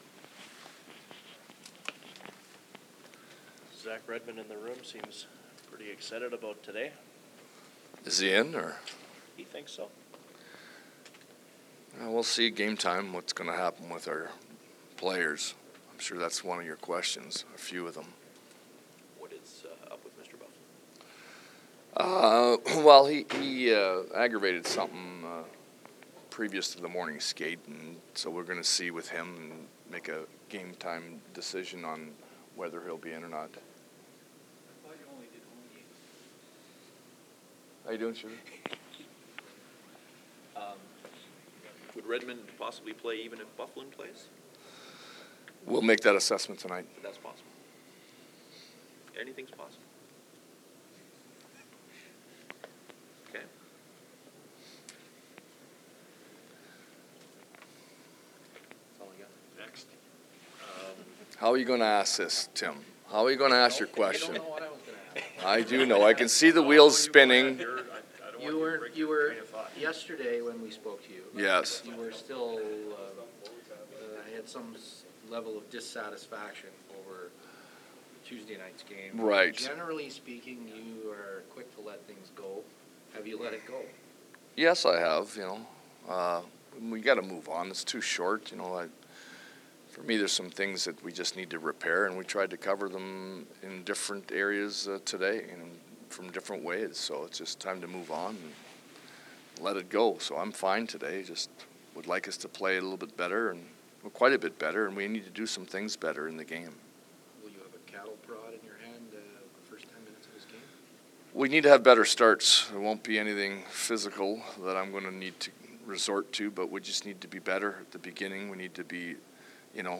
Categories: Coach Noel press conferenceWinnipeg Jets
Winnipeg Jets coach Claude Noel scrum
The Jets are in sunny Florida and coach Noel spoke to the media following his team’s skate in Sunrise: